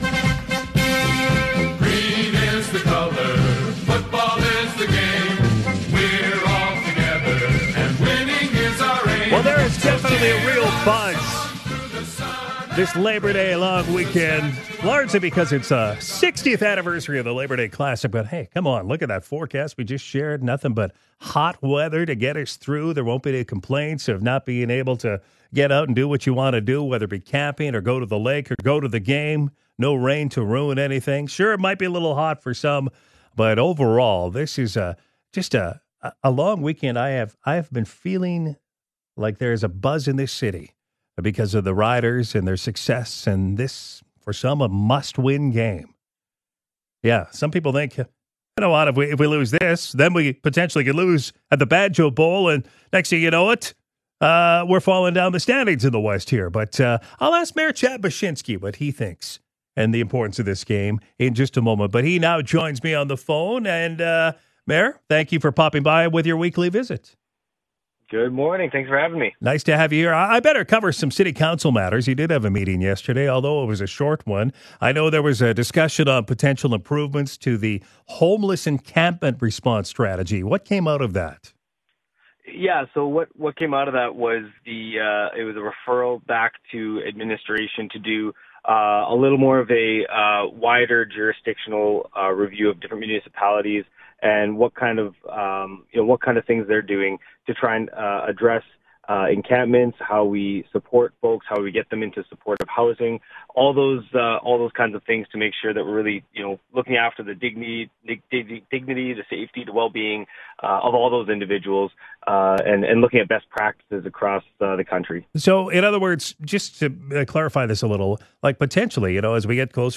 Mayor Chad Bachysnki is here for his weekly visit: Labour Day Classic, Green Day proclamation and a city council summary from yesterday’s meeting.